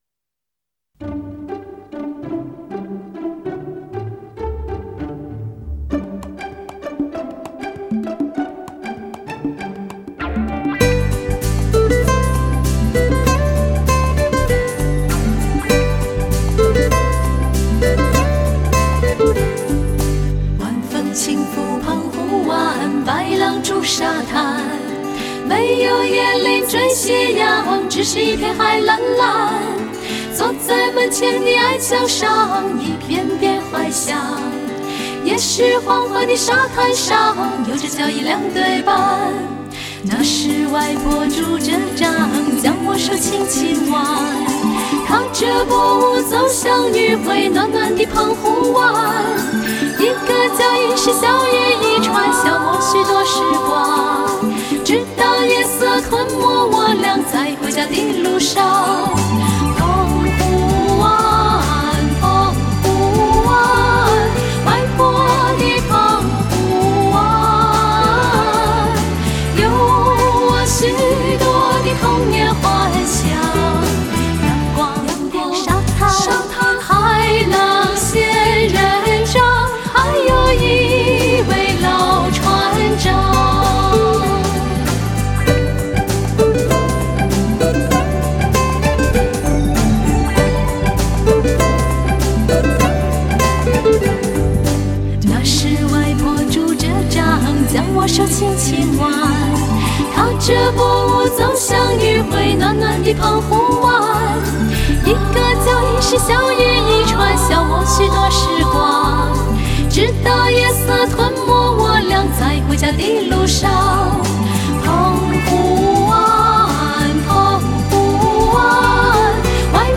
如何寻找一份寂静与解脱……清纯、甜美的歌声，
校园民谣听起来那么亲切动听.